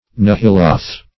Nehiloth \Ne"hi*loth\, n. pl. [Heb.] (Script.)